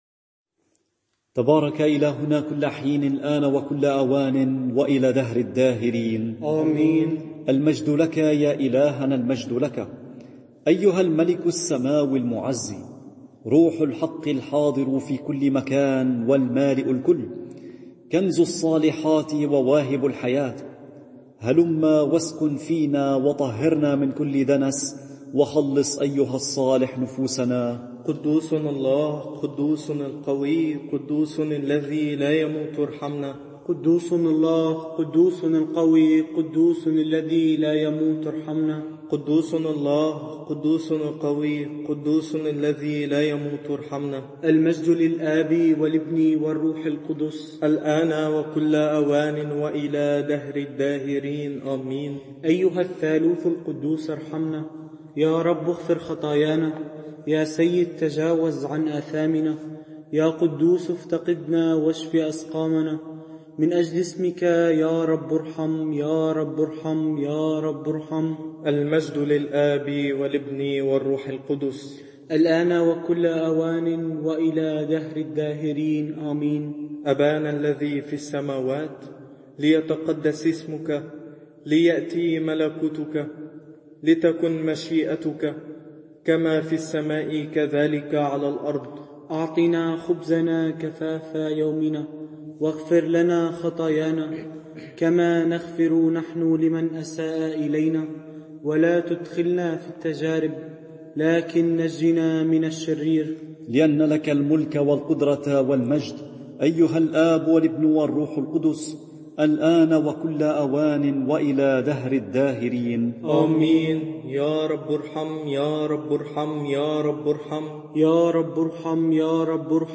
صلاة الساعة الأولى رهبان